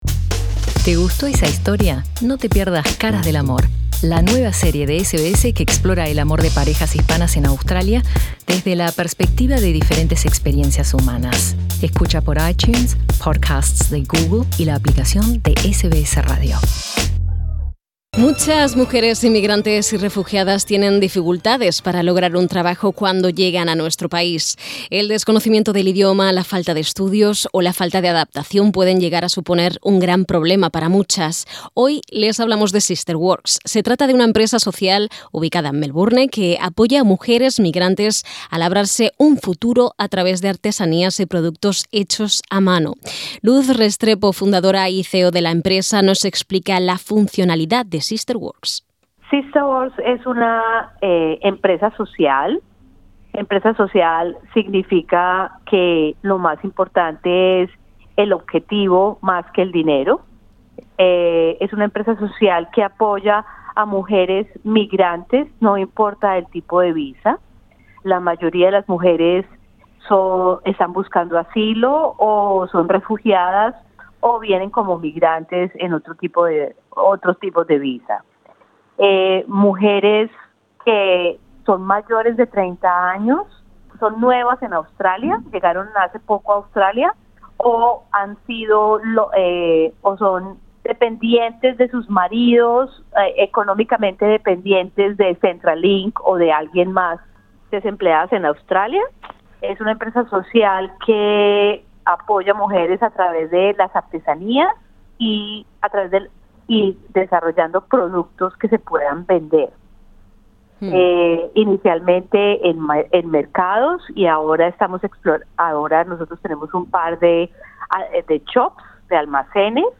Escucha su entrevista a SBS Spanish.